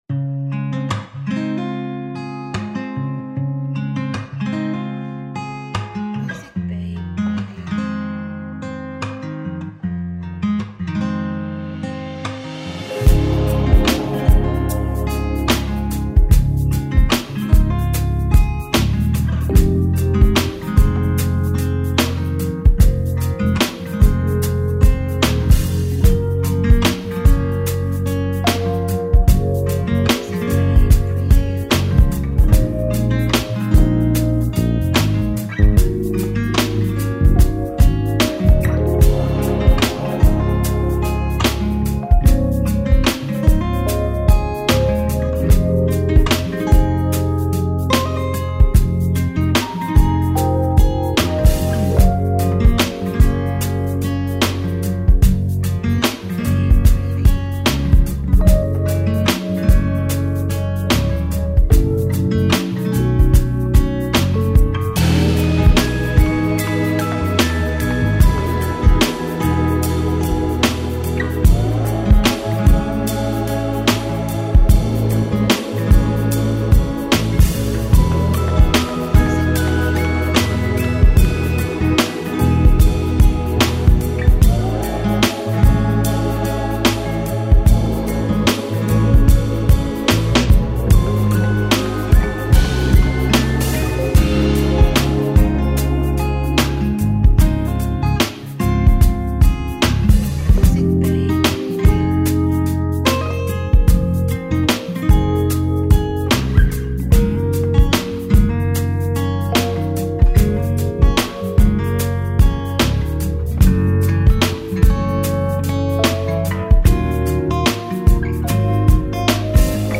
A category of tags that highlights lounge bar